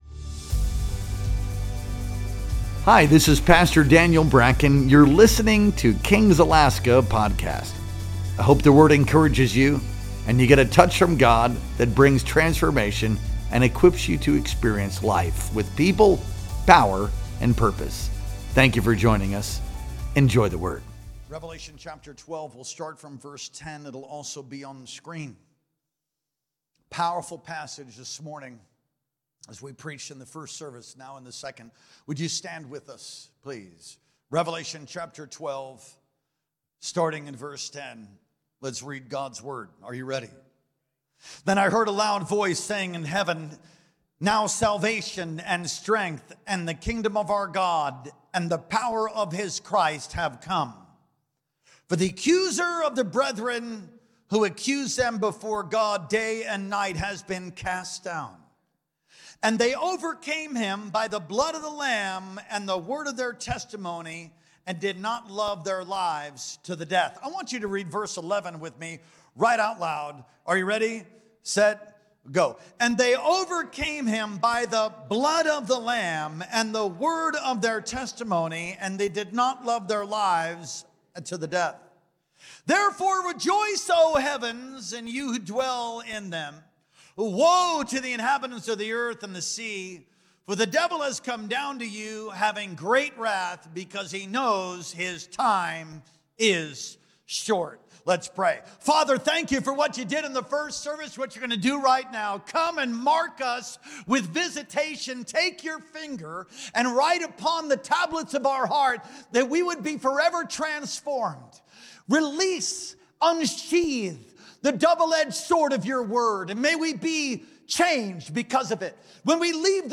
Our Sunday Worship Experience streamed live on March 23rd, 2025.